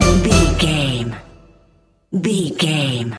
Aeolian/Minor
orchestra
harpsichord
silly
circus
goofy
comical
cheerful
perky
Light hearted
quirky